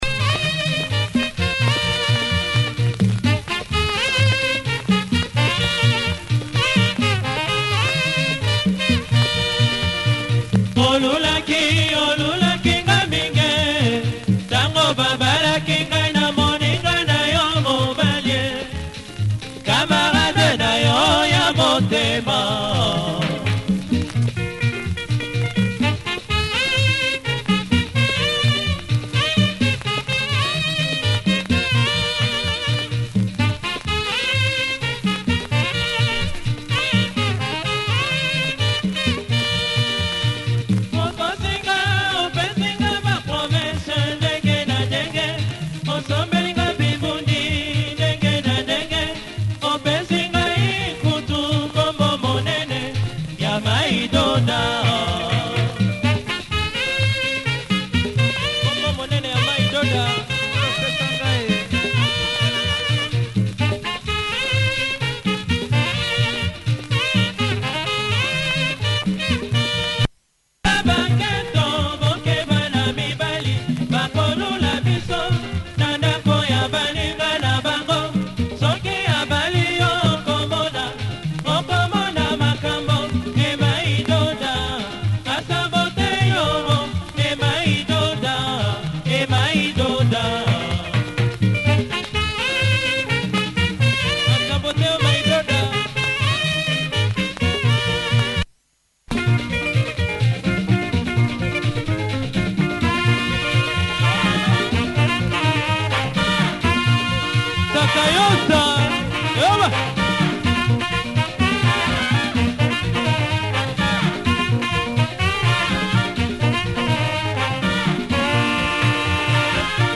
Has some wear but plays okay.